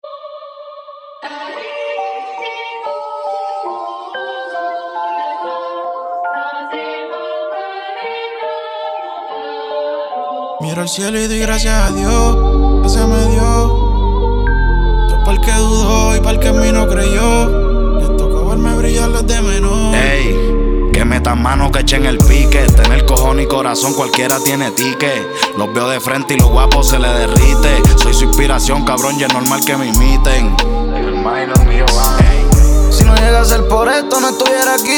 Страстные ритмы латино
Urbano latino Latin
Жанр: Латино